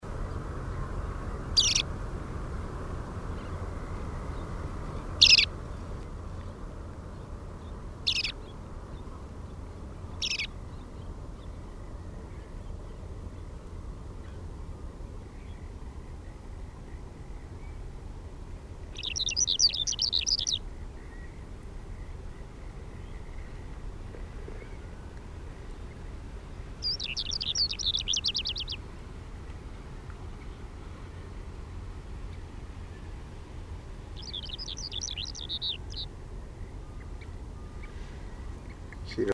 3. Eastern China
For comparison, here's calls and song plus one photo of easternmost populations of Lesser Short-toed Lark Calandrella (rufescens) cheleensis.
Calls and song, Happy Island, Hebei, China, May 2002